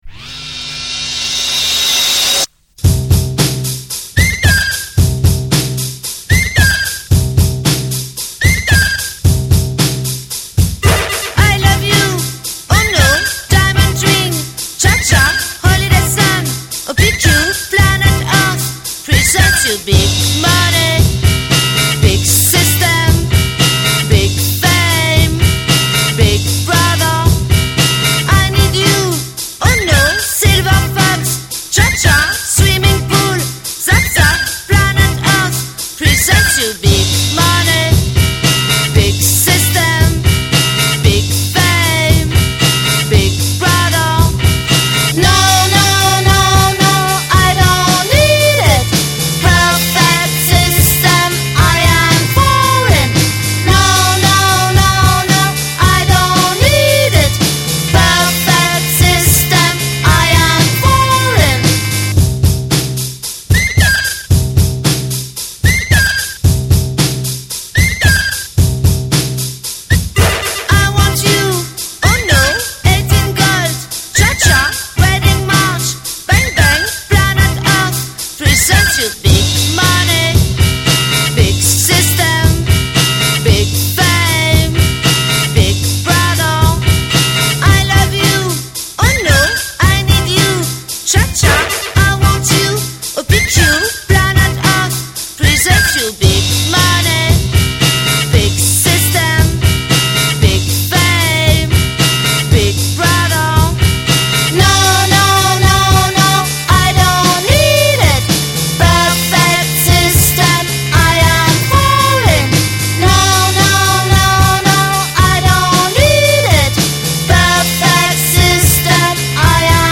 МУЗЫКА ИЗ РЕКЛАМ (JINGLE)